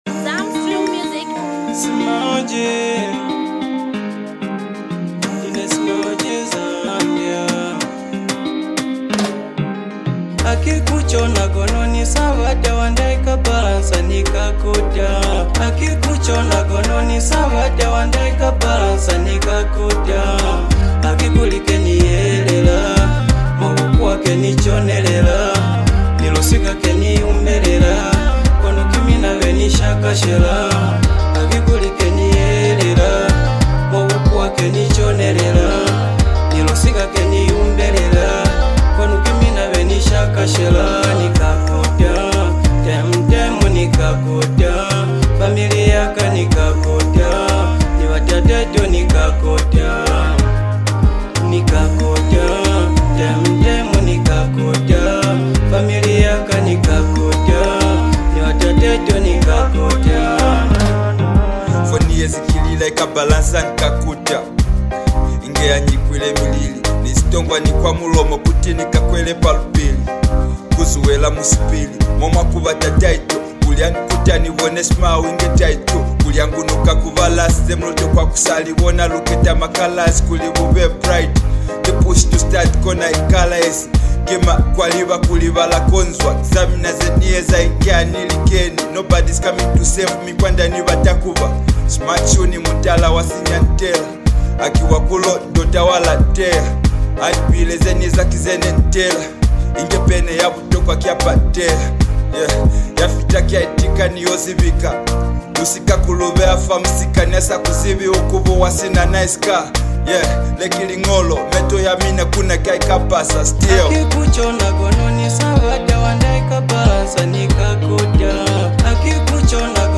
vibrant sound